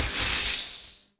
Air Pressure Release
A sharp hiss of compressed air escaping from a valve or pneumatic system
air-pressure-release.mp3